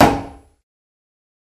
На этой странице собрана коллекция звуков кулаков — резкие удары, глухие толчки, звонкие столкновения.
Звук удара кулаком по металлу